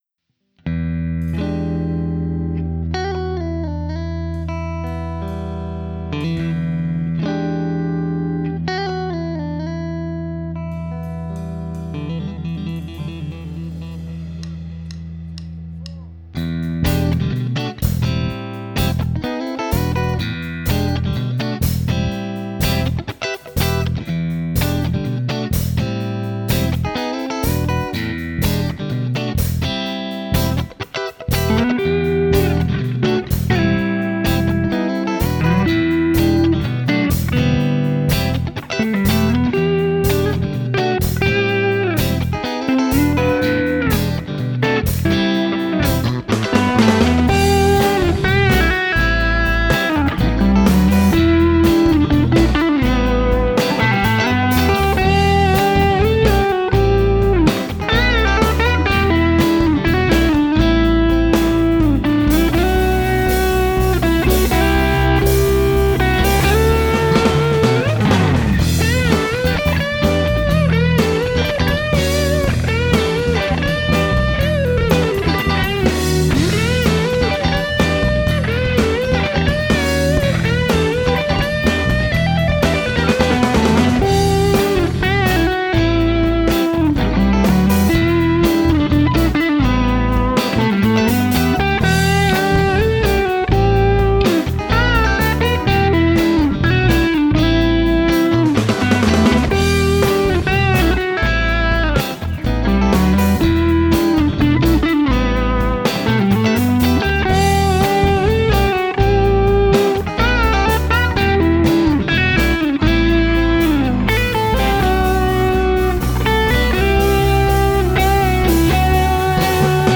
• Electric Guitar: Fender Stratocaster.
• Bass Guitar: Musicman Sterling StingRay.
• Organ: Nord Stage 2.
• Drums: Roland V-Drums triggering the Addictive Drums plug-in, by XLN Audio.